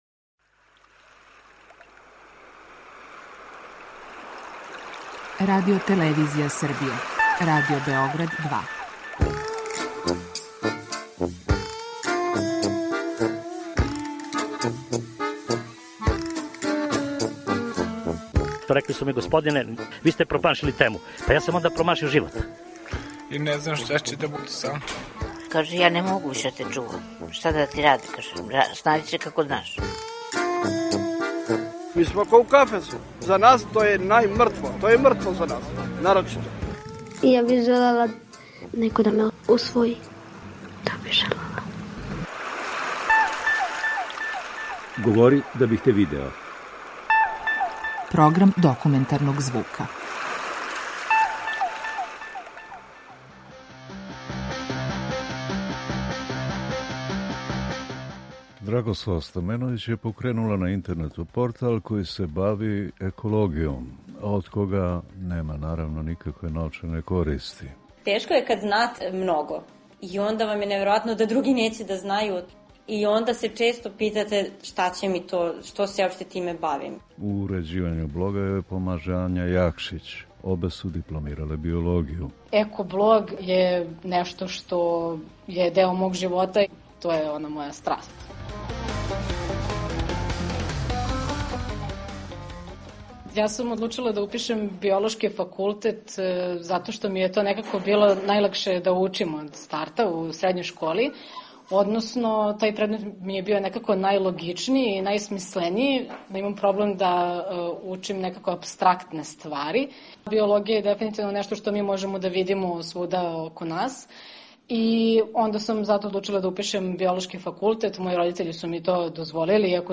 Документарни програм
Серија полусатних документарних репортажа